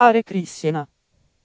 FliteTTS Chants.mp3